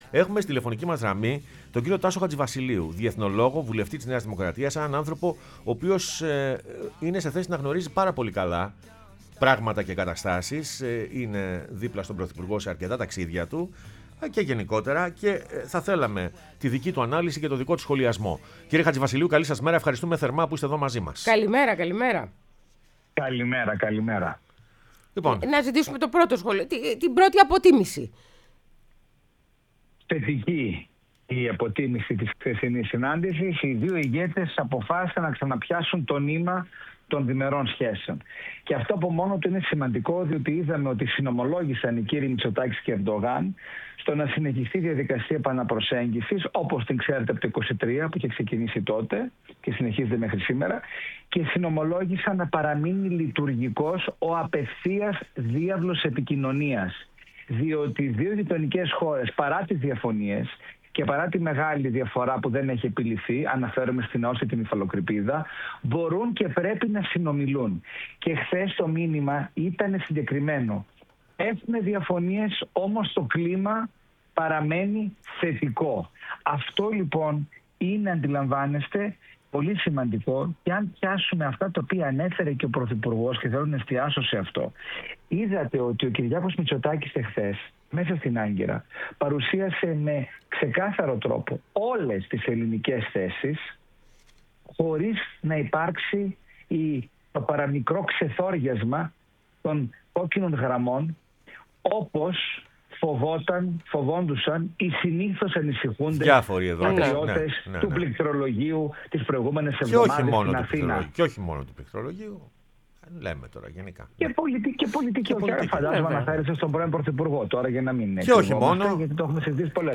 O Τασος Χατζηβασιλειου , διεθνολογος και βουλευτής ΝΔ μίλησε στην εκπομπή «Πρωινές Διαδρομές»